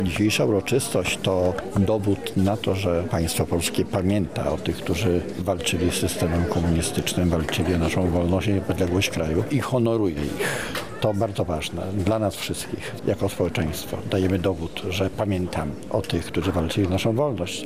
Krzysztof Szwagrzyk – mówi Krzysztof Szwagrzyk, wiceprezes Instytutu Pamięci Narodowej.